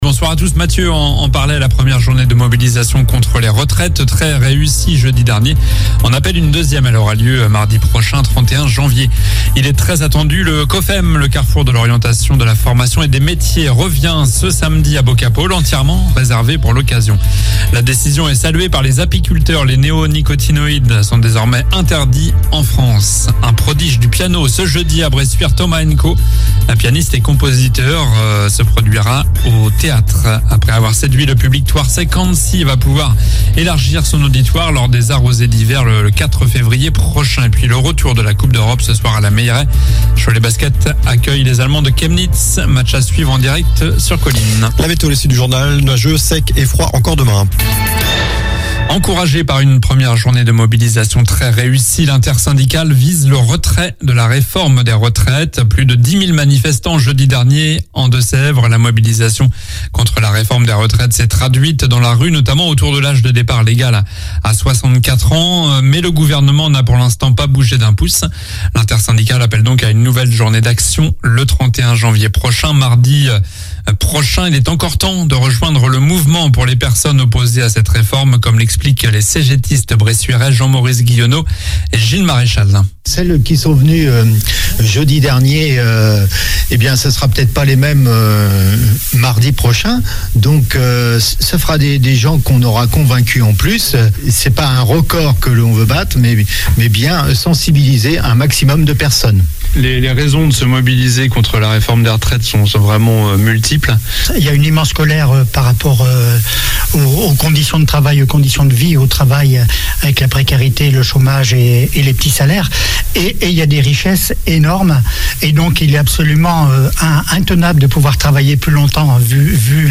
Journal du mardi 24 janvier (soir)